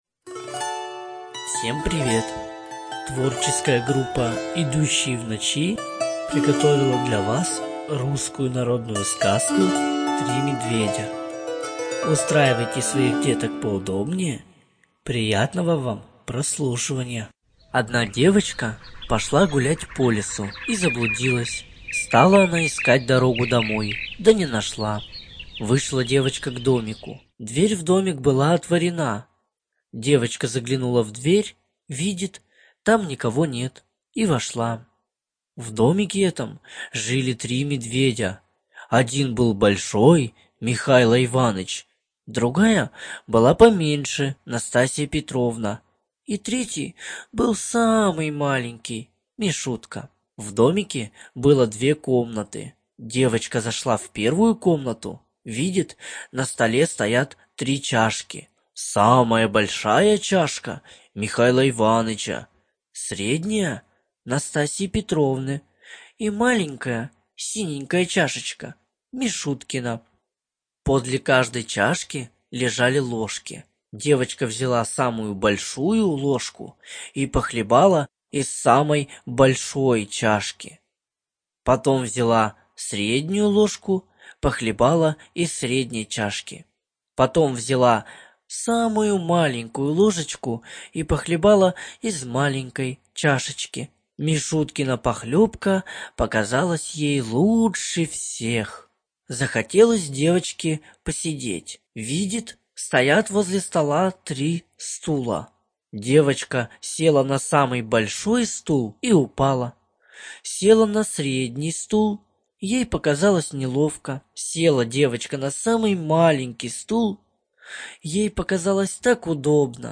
ЖанрСказки
Студия звукозаписиИдущие в ночи